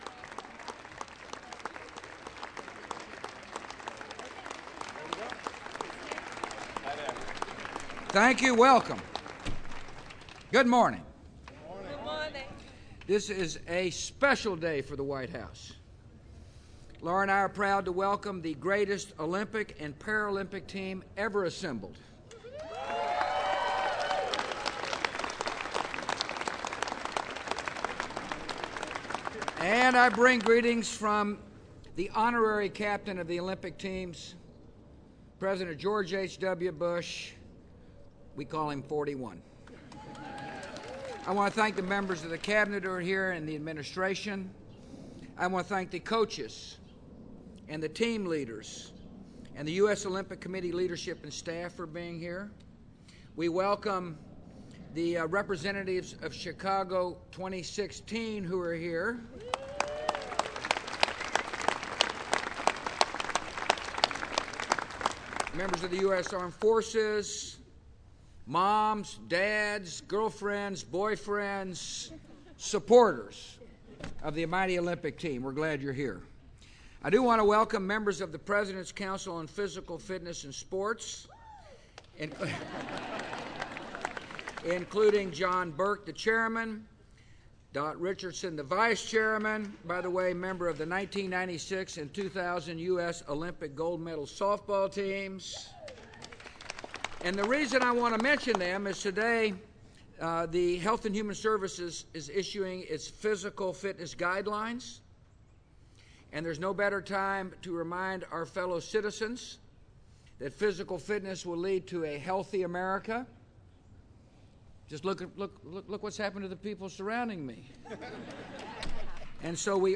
U.S. President George W. Bush speaks to members of the 2008 United States Summer Olympic and Paralympic Teams